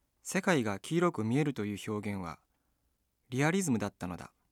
DAT(Digital Audio Tape)で収録した48kHz音源をリサンプルすることなくまとめたデータベースです。
オリジナルの音質を忠実に再現し、より豊かでクリアなサウンドを実現しています。
発話タスク ATR音素バランス503文
appbla_48k_M_sample.wav